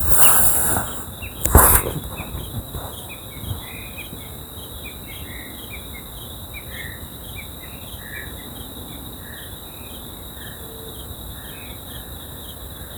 Great Rufous Woodcreeper (Xiphocolaptes major)
Country: Argentina
Location or protected area: Parque Nacional El Impenetrable
Condition: Wild
Certainty: Photographed, Recorded vocal